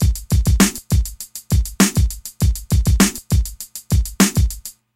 旧式嘻哈
描述：尼斯打击乐
Tag: 100 bpm Hip Hop Loops Drum Loops 857.20 KB wav Key : Unknown